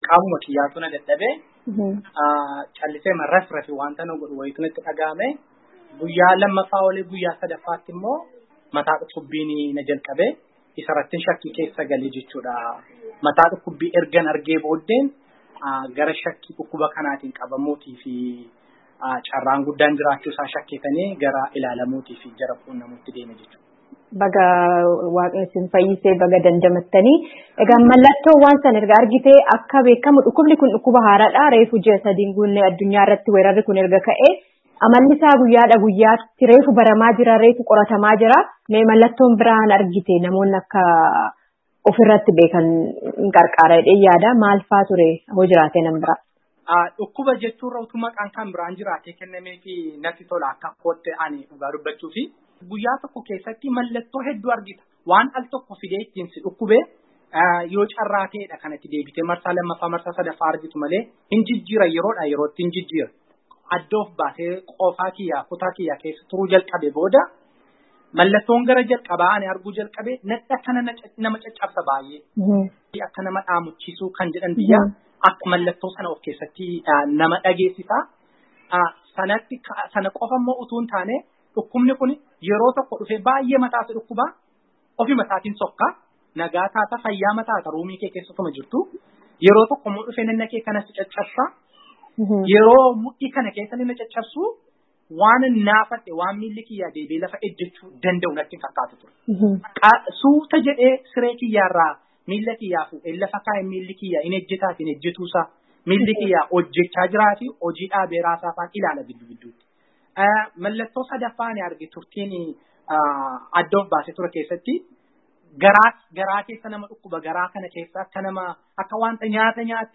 gaafii fi deebii